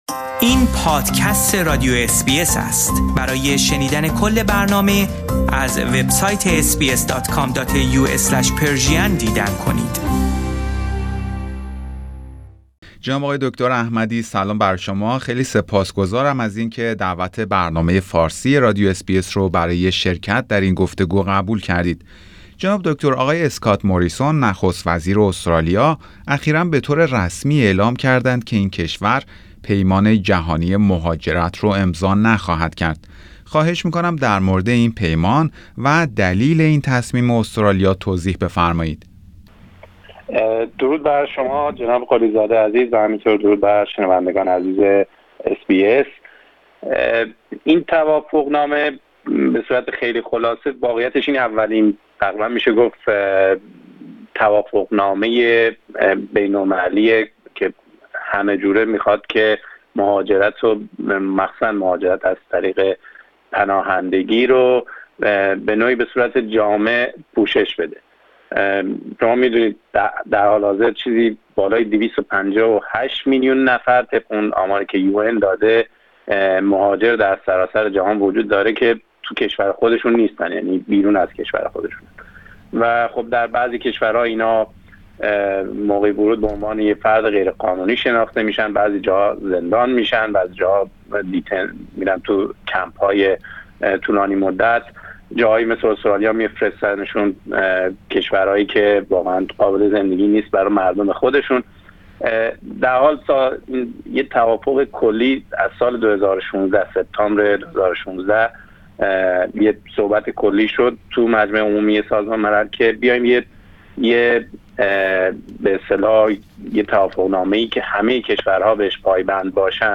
در این گفتگو به بررسی دلایل احتمالی خودداری استرالیا از امضای این پیمان و همچنین تاثیرات احتمالی نتایج انتخابات فدرال آتی استرالیا روی سیاست های مهاجرتی این کشور پرداخته شده است. توجه شما به شنیدن این گفتگو جلب می شود.